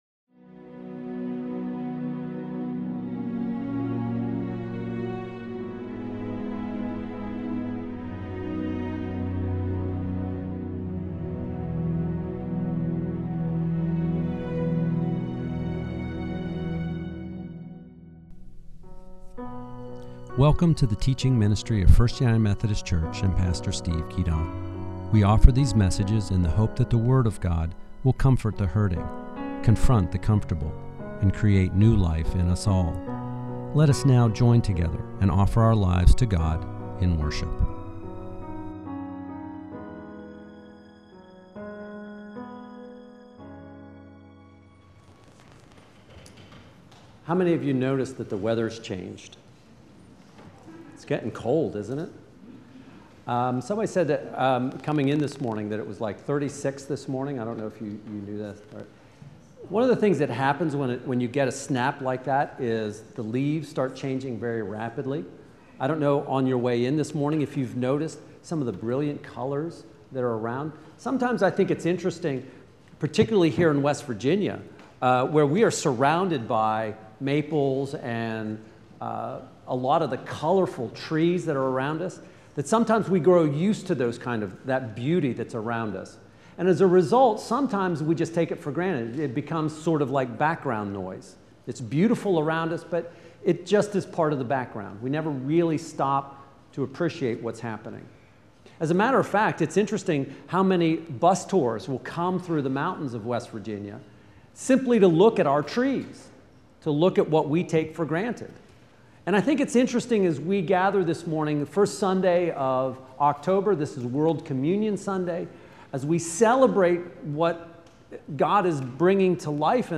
In our study this week, we look at the why and wherefore of God's commandments to provide for the poor, foreigners and widows by allowing them to glean from the fields. This week's stewardship message is as important today as it was for the Hebrews looking to become a holy people of God.